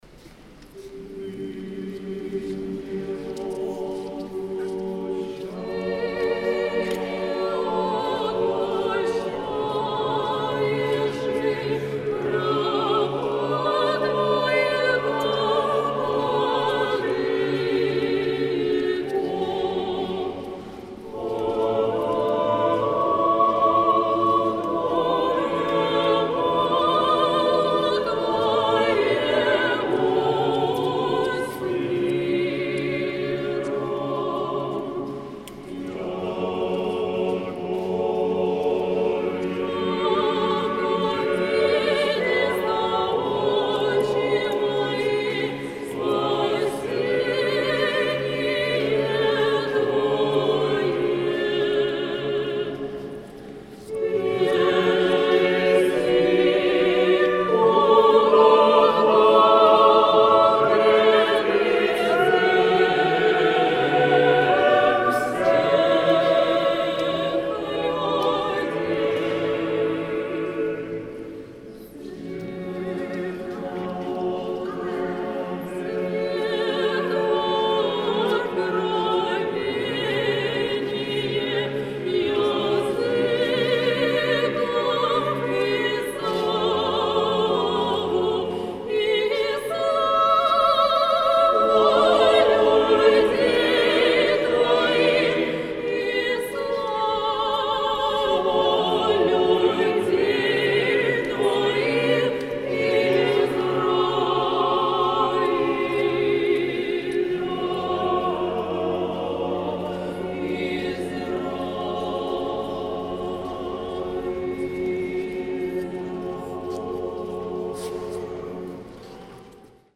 Архиерейский хор кафедрального собора.